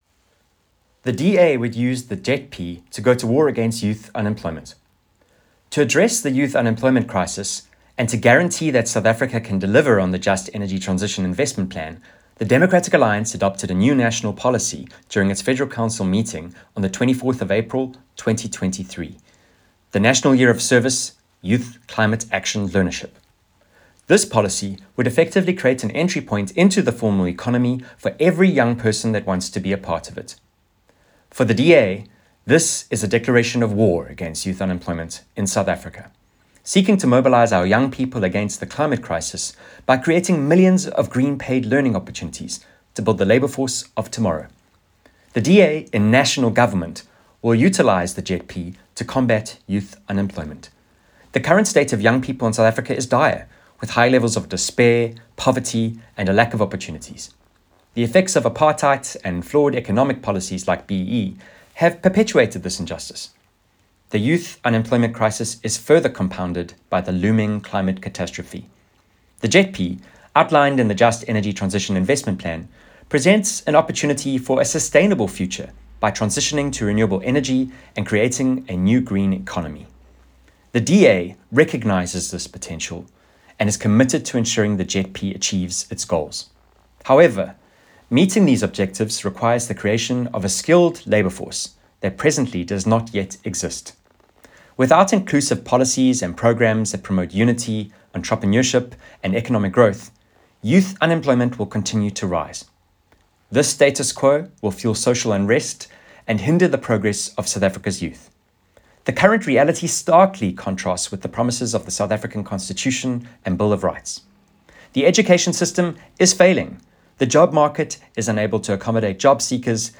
soundbite by Traverse Le Goff MP.